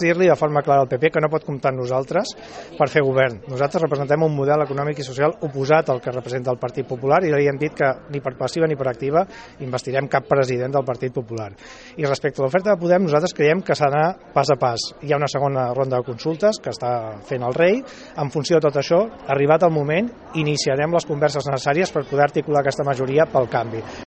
Rodríguez deia un ‘no’ prou clar al PP. “No pot comptar amb nosaltres perquè representem un model econòmic i social oposat, i ni per activa ni per passiva investirem cap president del PP”, va manifestar el diputat socialista en declaracions a Ràdio Calella TV.